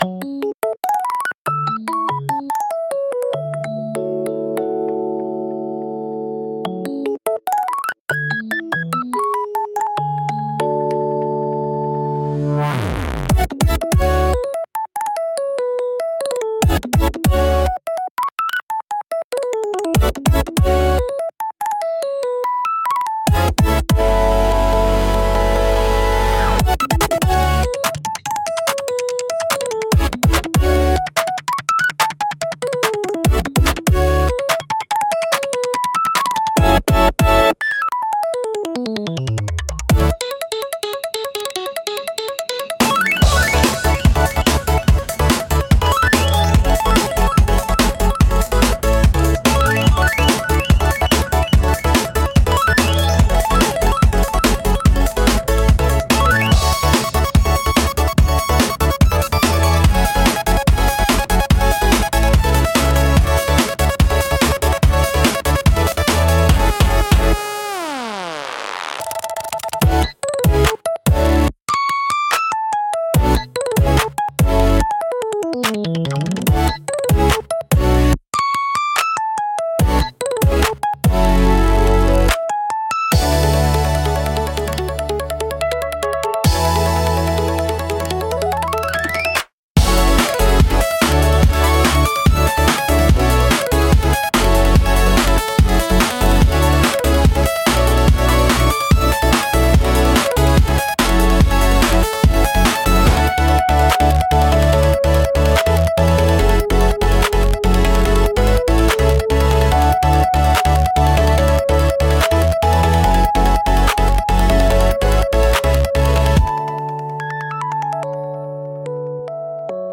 聴く人の感覚を刺激し、緊張感や好奇心を喚起しながら、独自の雰囲気を強調する効果があります。